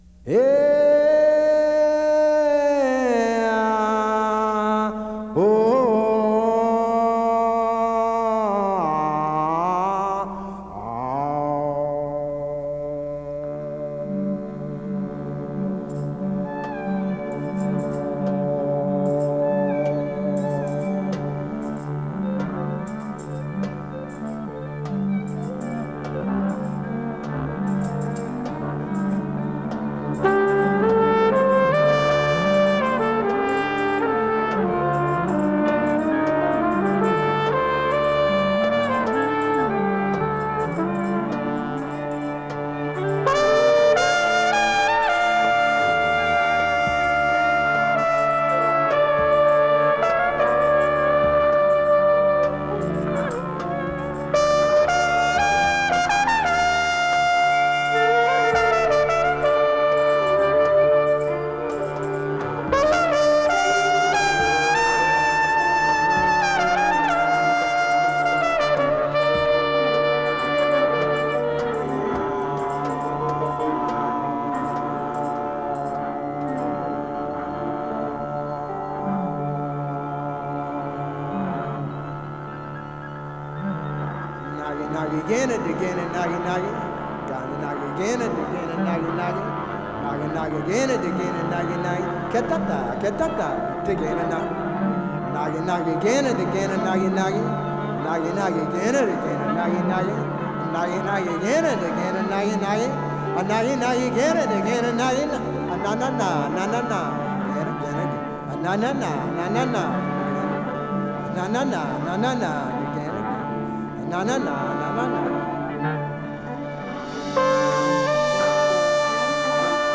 trumpet
mantra-like